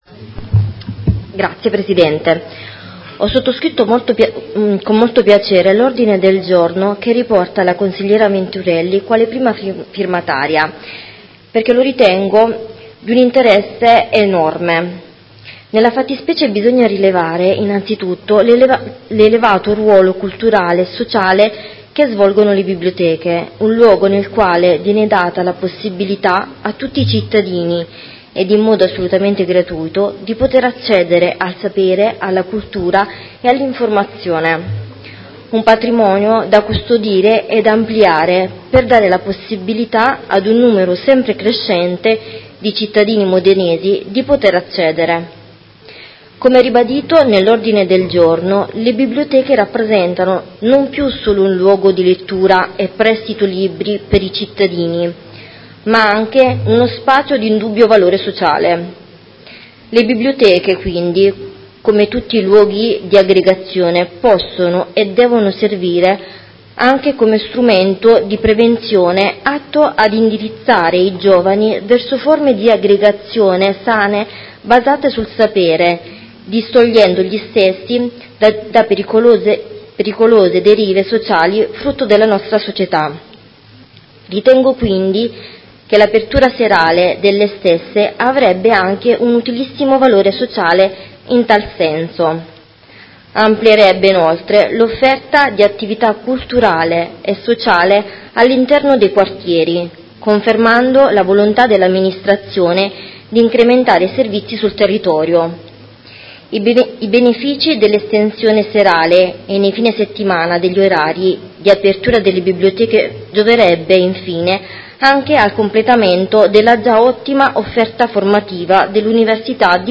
Katia Parisi — Sito Audio Consiglio Comunale
Seduta del 19/09/2019 Dibattito. Ordine del Giorno Prot. Gen. n. 193394 presentato dai Consiglieri Venturelli, Carriero, Lenzini, Manicardi, Fasano, Guadagnini, Tripi, Reggiani, Carpentieri (PD), Scarpa, Stella (Sinistra per Modena), Parisi (Modena Solidale) e Consigliera Aime (Verdi) avente per oggetto: Una biblioteca aperta in ogni quartiere nel fine settimana ed in orario serale – prima firmataria Consigliera Venturelli